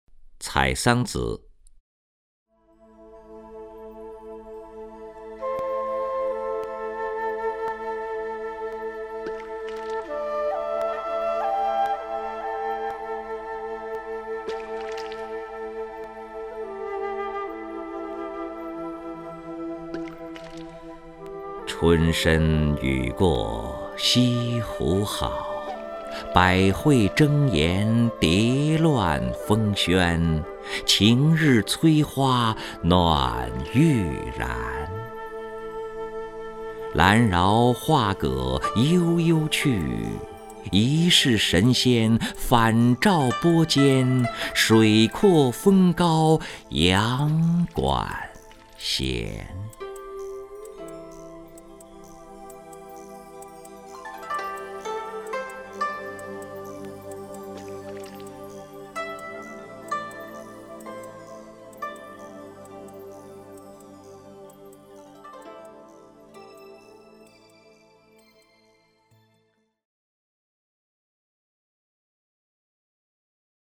任志宏朗诵：《采桑子·春深雨过西湖好》(（北宋）欧阳修)
名家朗诵欣赏 任志宏 目录